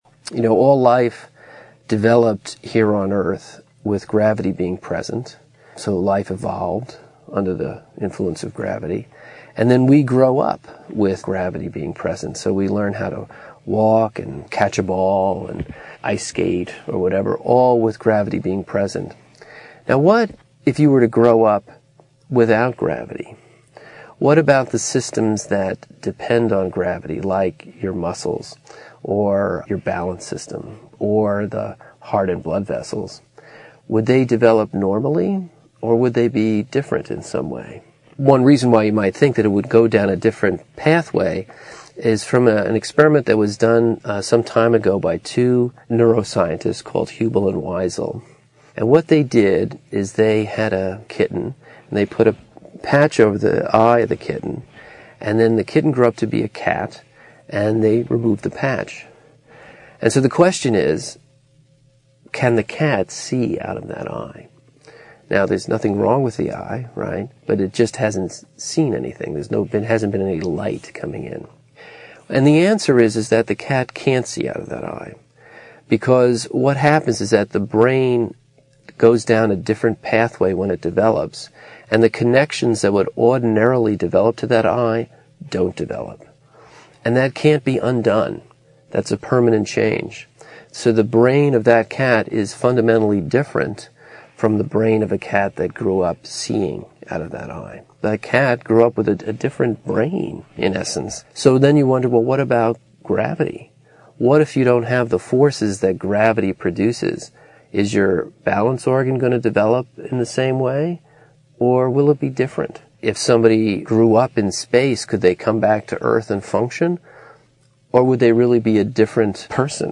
TED演讲:引力与人体(2) 听力文件下载—在线英语听力室